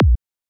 FX